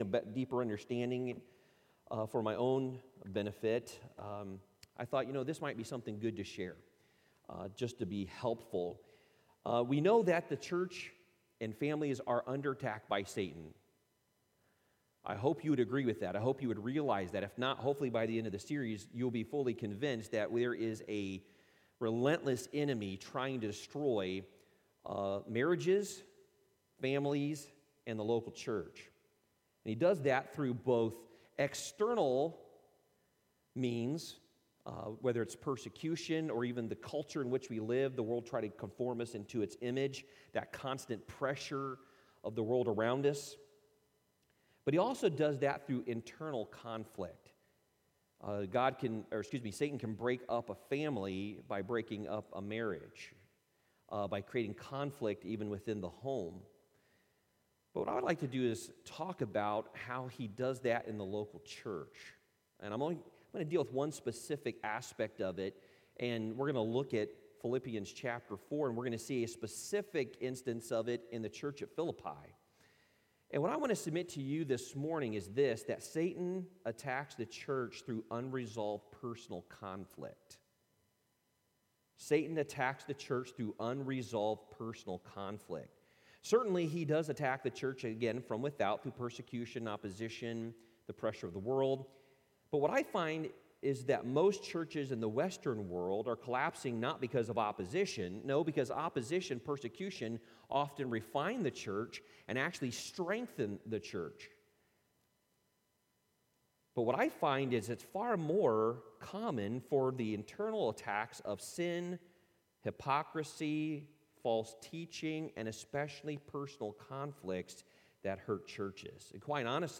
Worship Service 02/14/2021